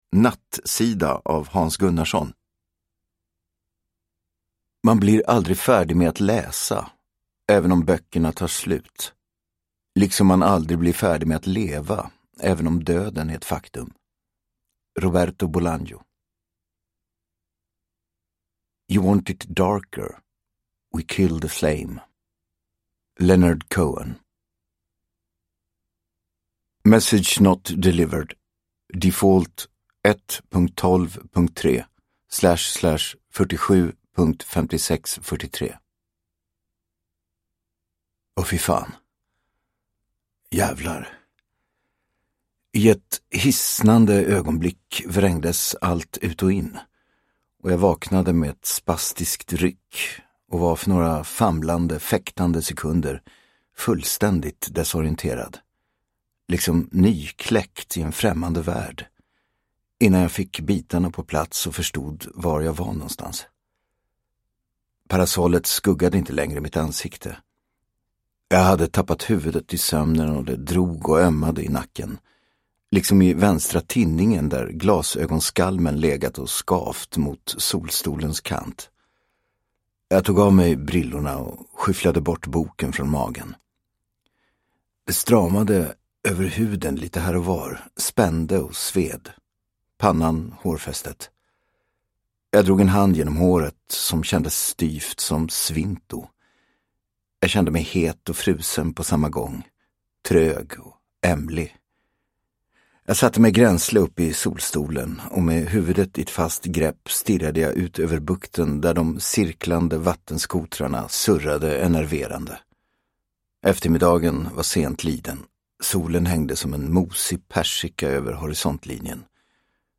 Nattsida – Ljudbok – Laddas ner